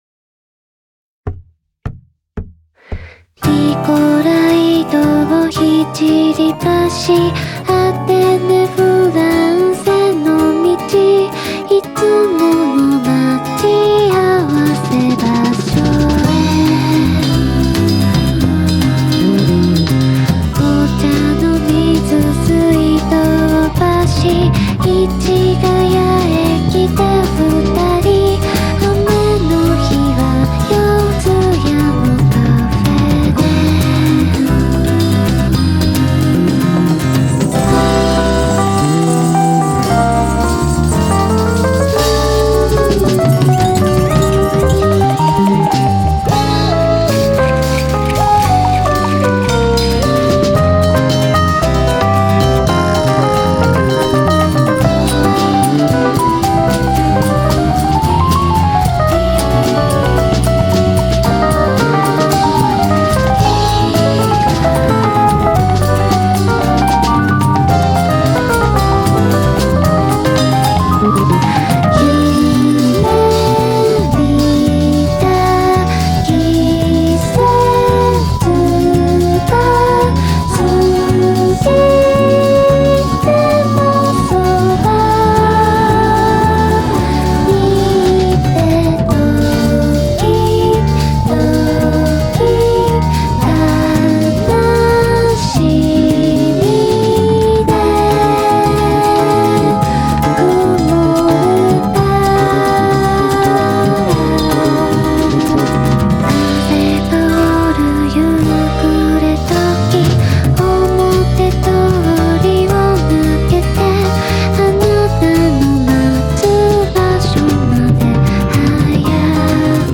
BPM112-112
Audio QualityPerfect (High Quality)